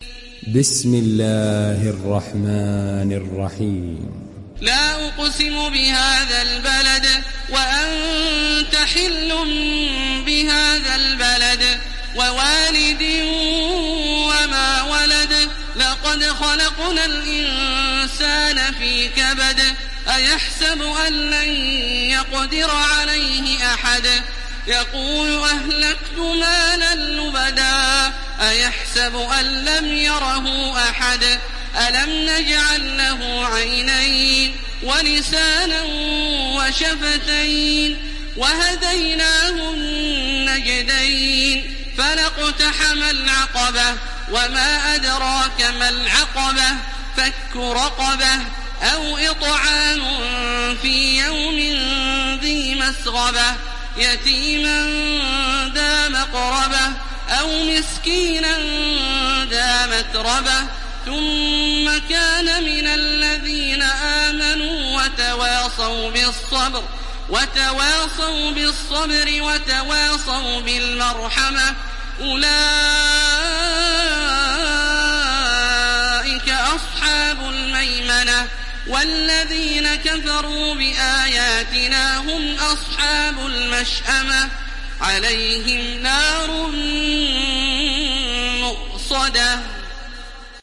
دانلود سوره البلد mp3 تراويح الحرم المكي 1430 روایت حفص از عاصم, قرآن را دانلود کنید و گوش کن mp3 ، لینک مستقیم کامل
دانلود سوره البلد تراويح الحرم المكي 1430